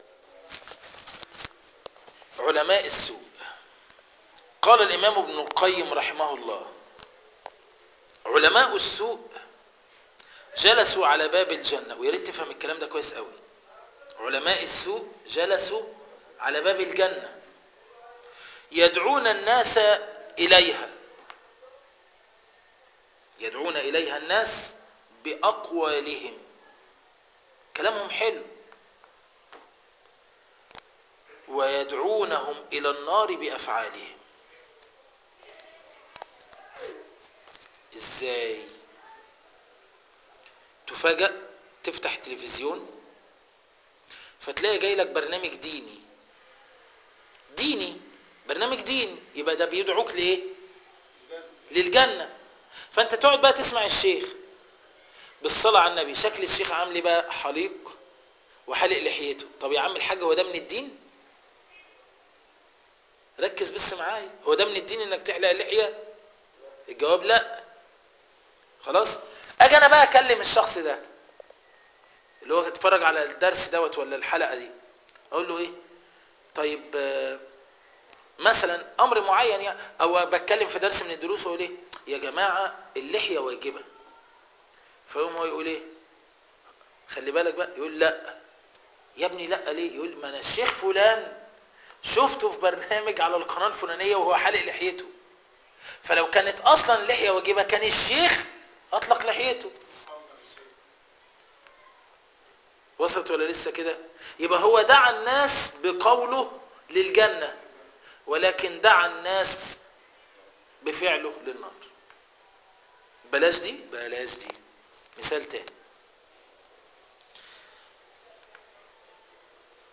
تفاصيل المادة عنوان المادة حق العلماء الدرس الخامس تاريخ التحميل الخميس 28 فبراير 2013 مـ حجم المادة 9.16 ميجا بايت عدد الزيارات 829 زيارة عدد مرات الحفظ 291 مرة إستماع المادة حفظ المادة اضف تعليقك أرسل لصديق